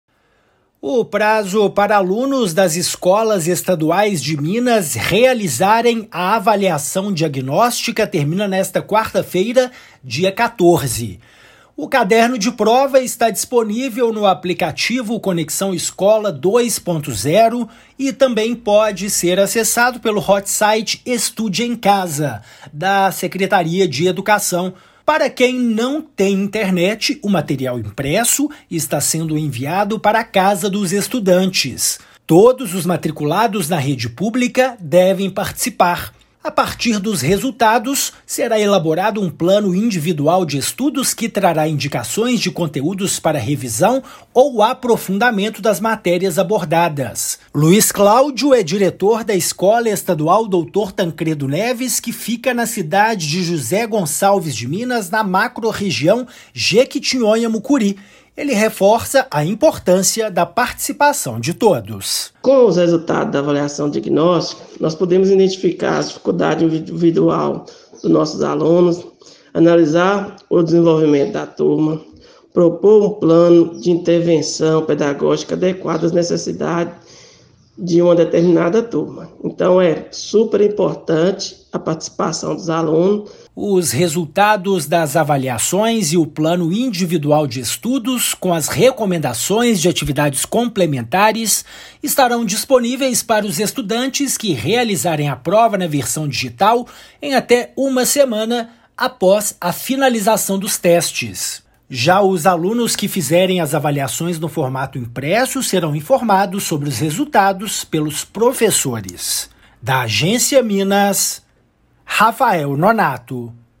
Prova disponível de forma remota e impressa revela de que forma estudante acompanha a matéria e em quais pontos precisa investir mais. Ouça a matéria de rádio.
MATÉRIA_RÁDIO_AVALIAÇÃO_DIAGNÓSTICA.mp3